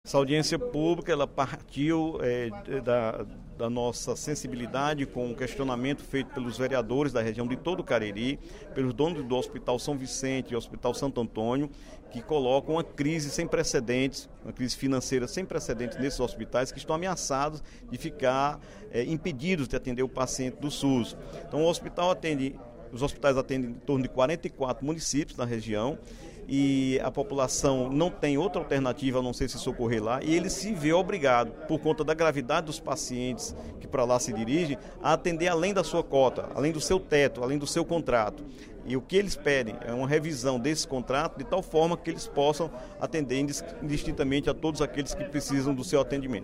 O deputado Dr. Santana (PT) anunciou, durante o primeiro expediente da sessão plenária desta quarta-feira (03/05), que será realizada em Barbalha audiência pública para discutir a situação dos hospitais São Vicente e Santo Antônio, localizados no município.